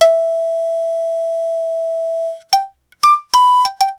Marimba Sound Effects - Free AI Generator & Downloads
marimba-playing-three-not-nfsjayi7.wav